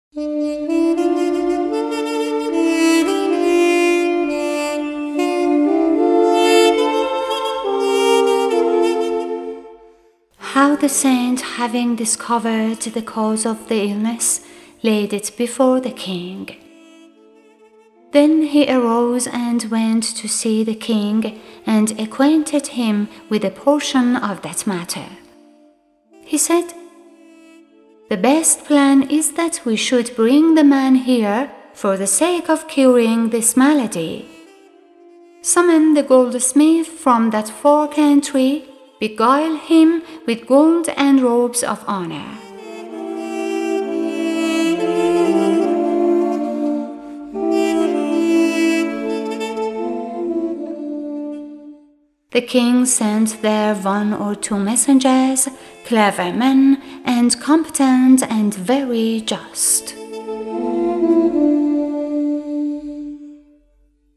Music by